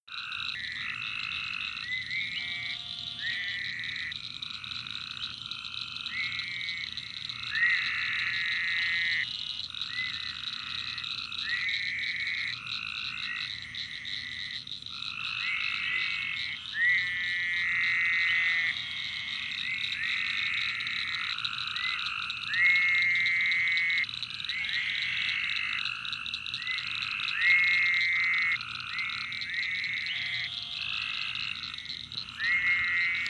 Then, down the street near a small pond.  I can hear at least four different frog species in here.  The tree frog trill, a castanet rattle (yes, that’s also a frog), the whistling/buzzing “yell” of what I think is a leopard frog, and the occasional deep bass of a bullfrog.  A lot happening in this pond that night!
marsh-frogs.mp3